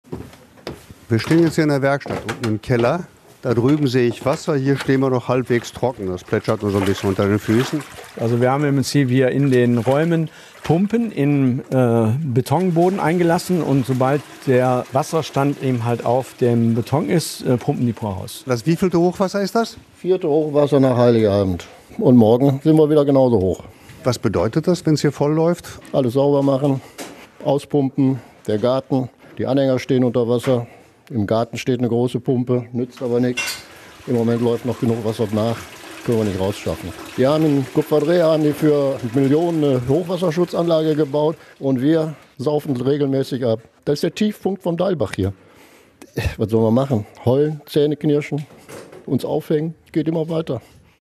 deilbach-schwappt-in-die-werkstatt-.mp3